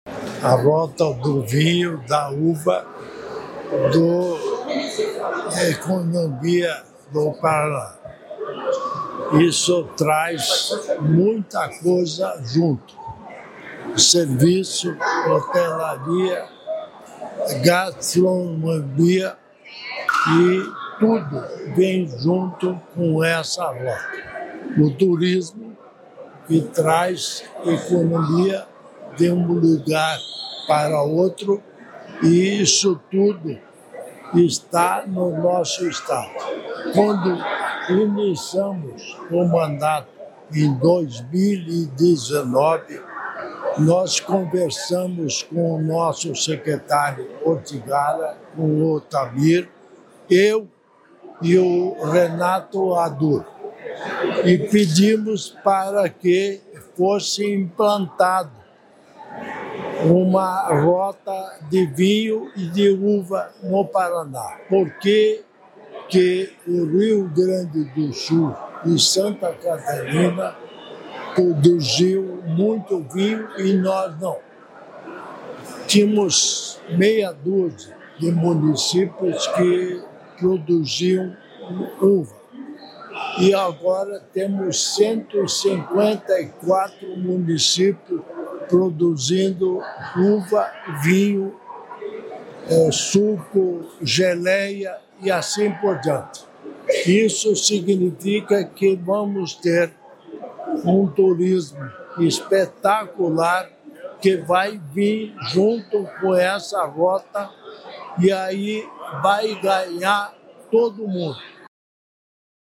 Sonora do governador em exercício Darci Piana sobre a Rota da Uva & Vinho